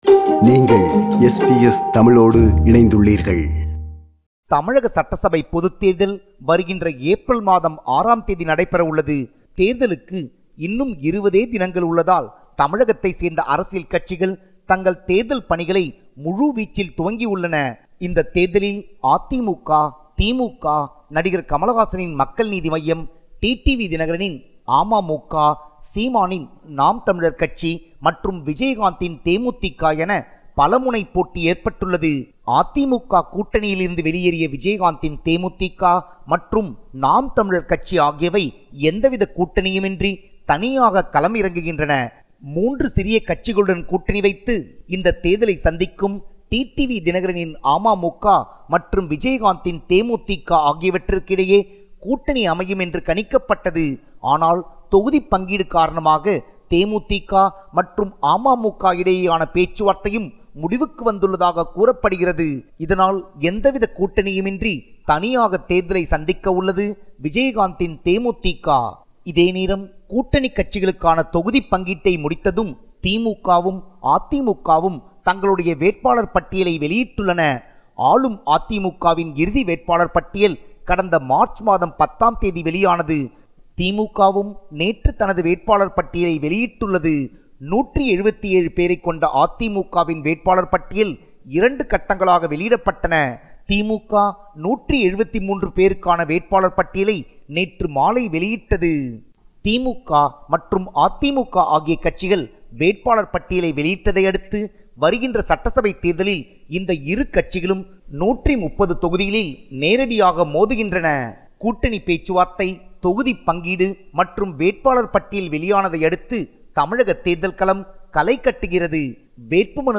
இணைகிறார் நமது தமிழக செய்தியாளர்